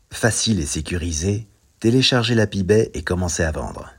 Bouclage 1